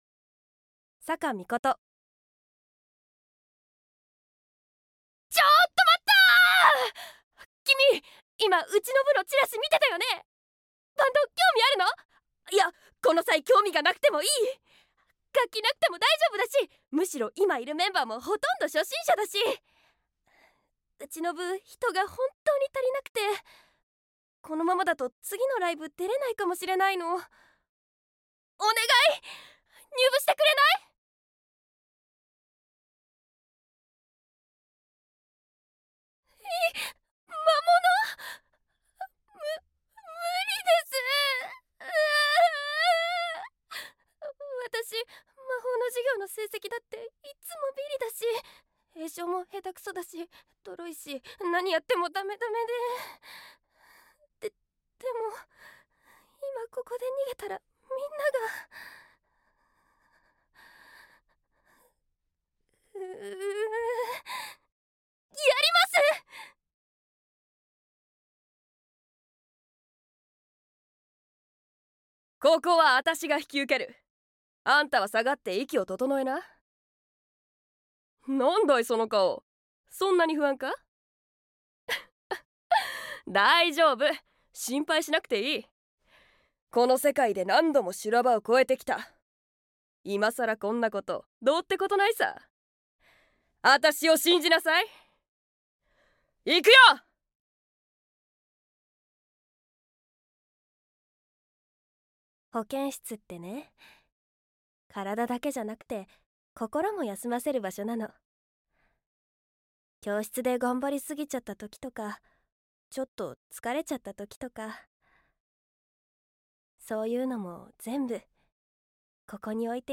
サンプルボイス
方言 名古屋弁